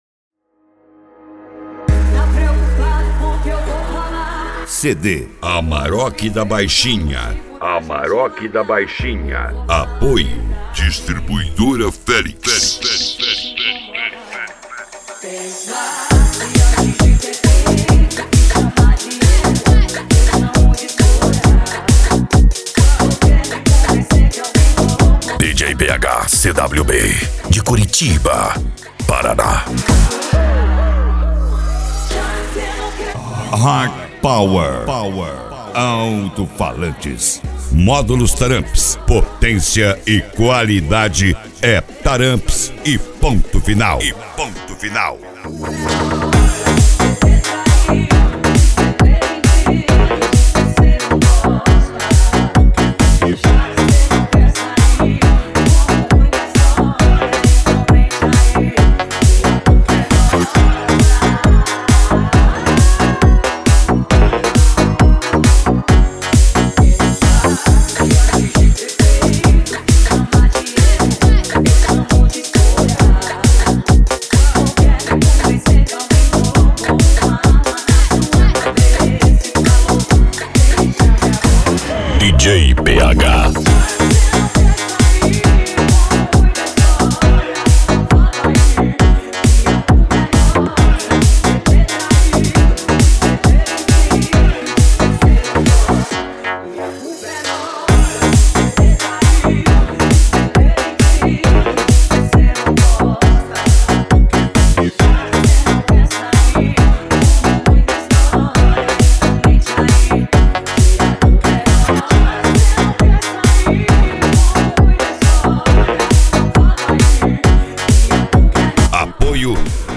Eletronica
Funk
PANCADÃO
SERTANEJO